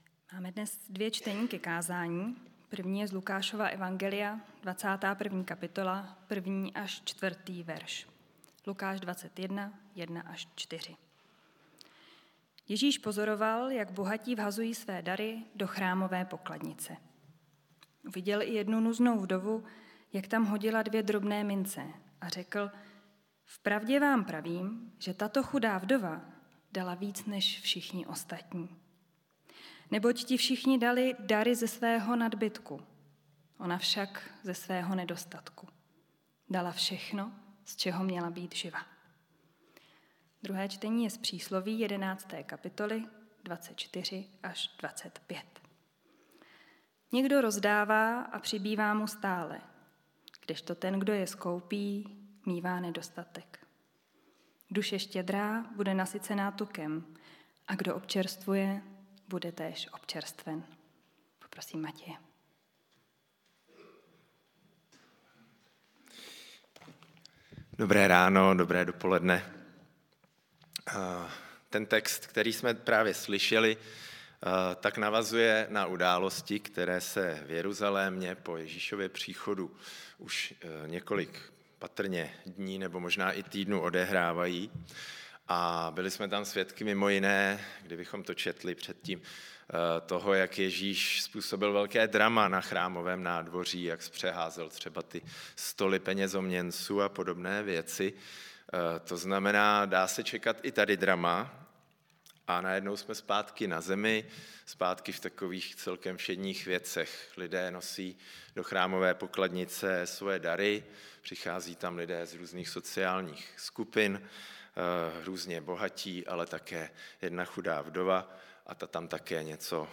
Nedělní bohoslužby přehrát